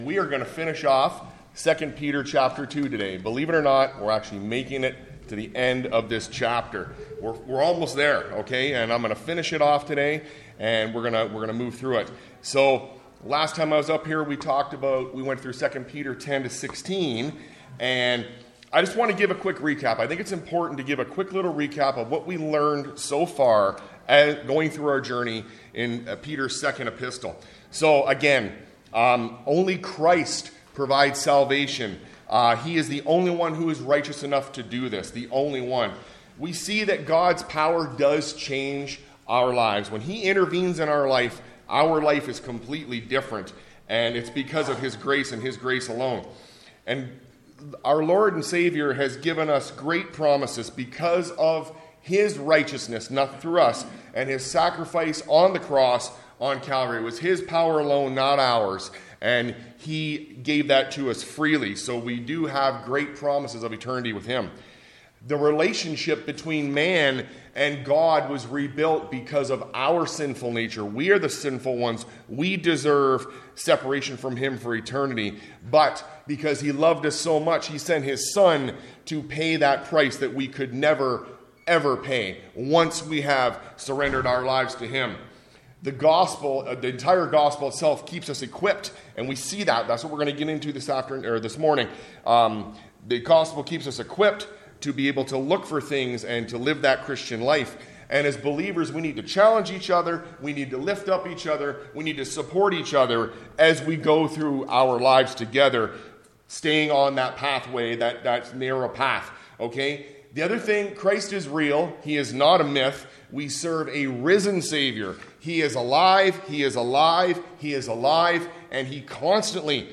A message from the series "The Word Together."